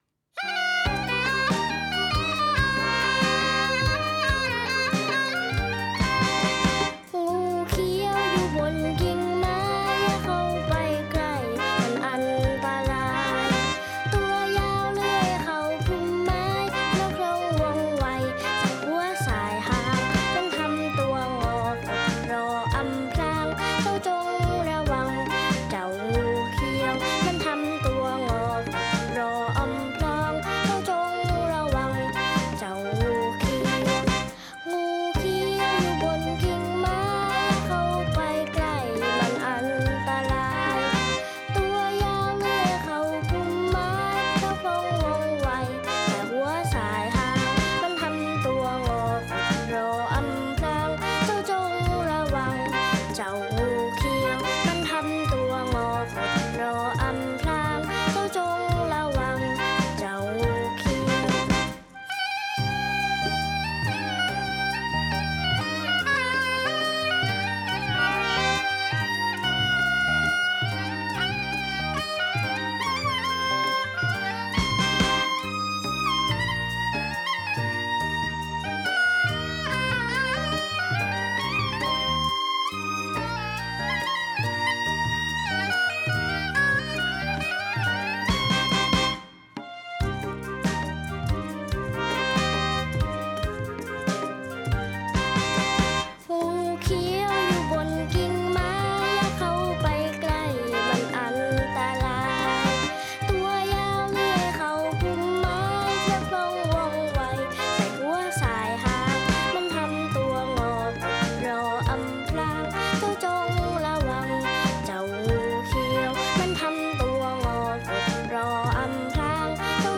ไฟล์เพลงพร้อมเสียงร้อง